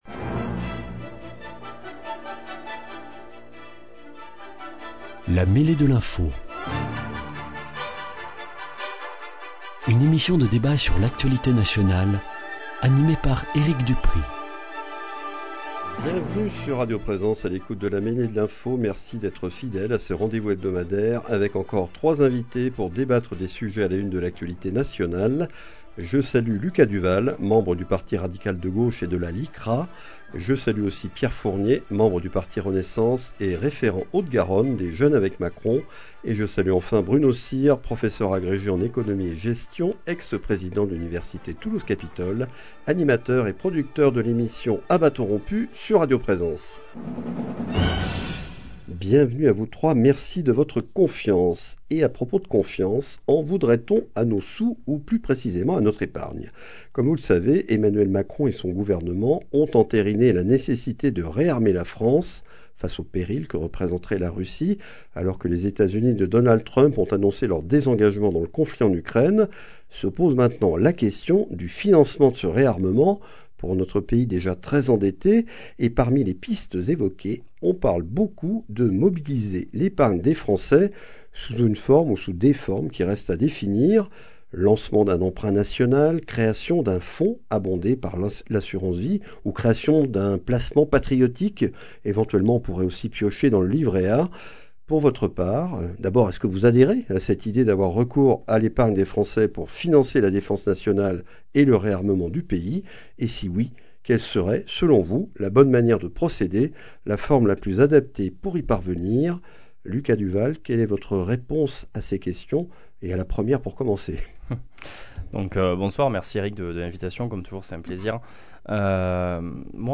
Nous débattons aujourd’hui de la possibilité de mobiliser l’épargne des Français pour financer le réarmement du pays, puis des accusations contre E. Macron qui chercherait à faire peur aux citoyens en exagérant la menace russe.